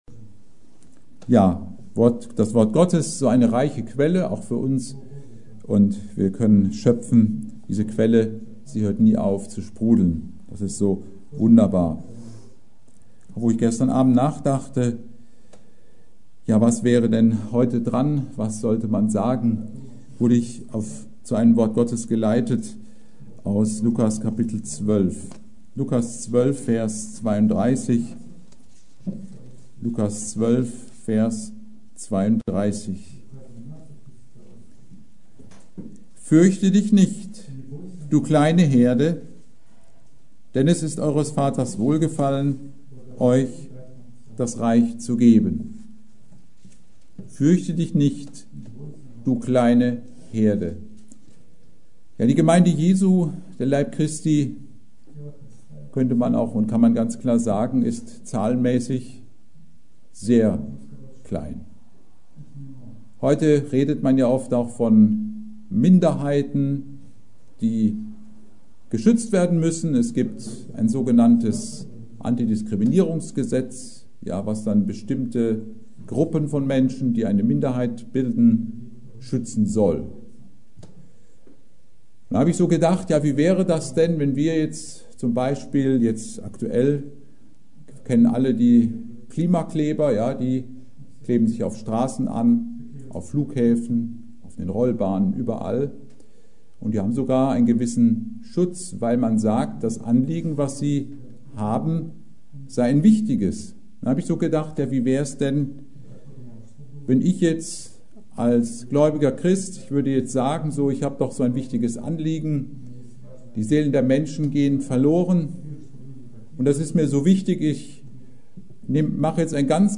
Predigt: Fürchte dich nicht, du kleine Herde!